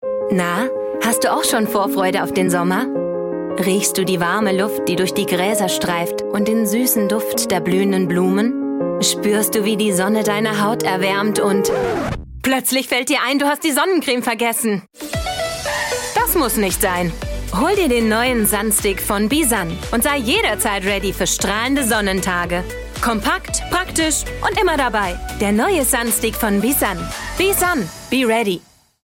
Hier ein paar Audio- und Video-Beispiele – von sinnlich, ernst oder sachlich über unbeschwert und heiter hin zu aufgebracht und verzweifelt.
Werbung & Commercials